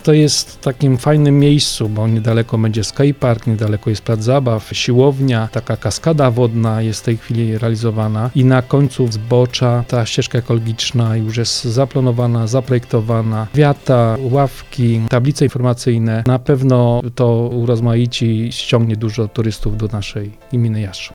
O szczegółach projektu, Andrzej Bracha Burmistrz Jastrzębia: